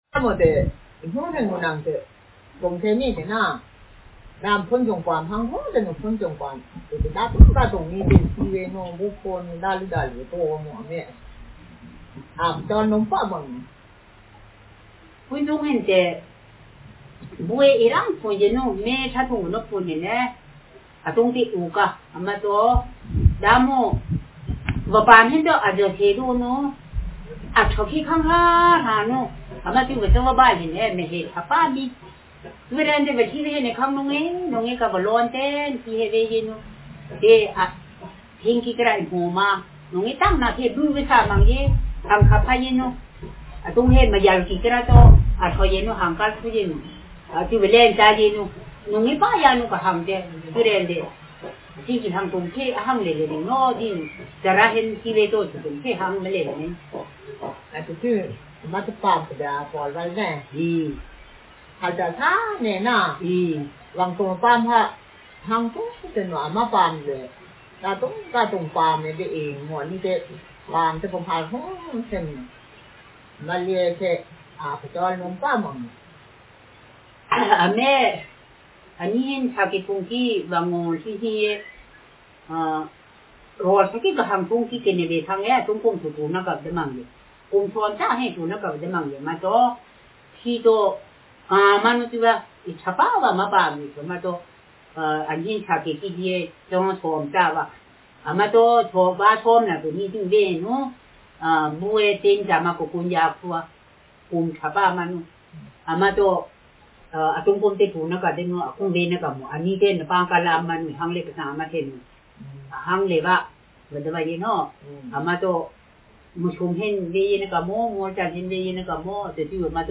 Speaker sex f Text genre conversation